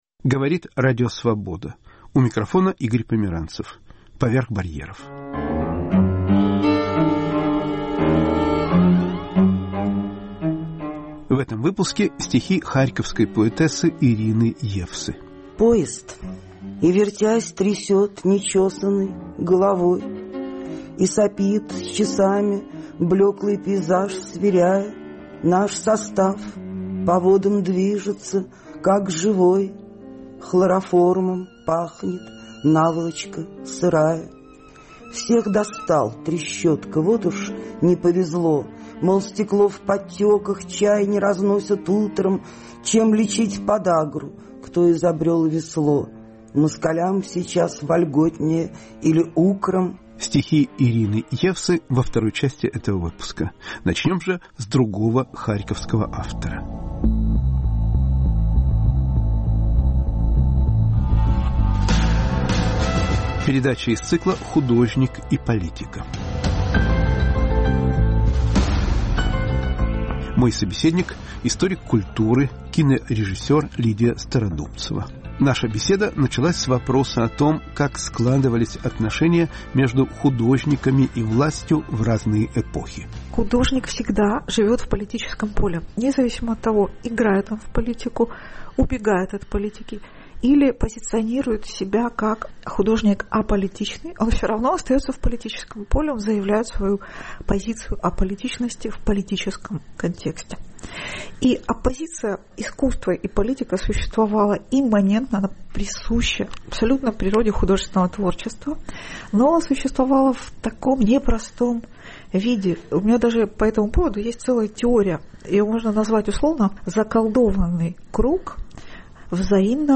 Разговор с историком культуры